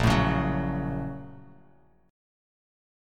BbmM7#5 chord